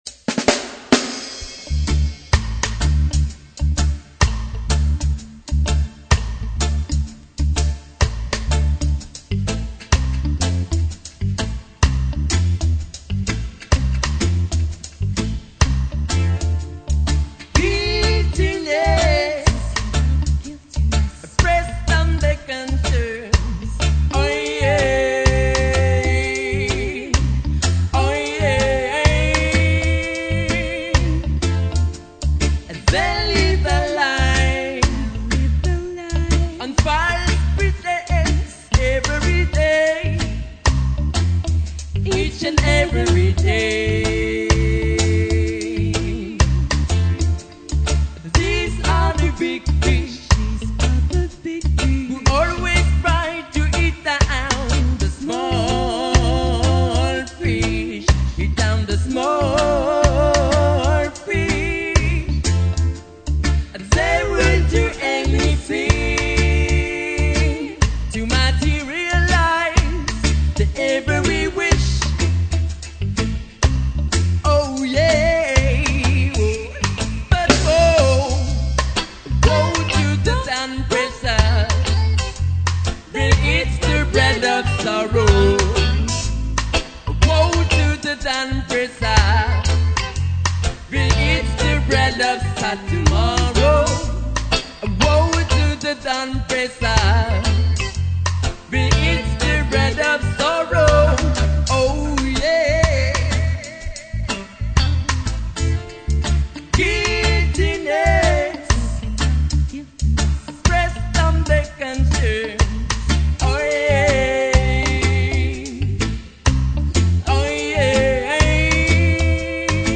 CD démo 5 titres Live ! (2000)
Enregistré live à la Lune des Pirates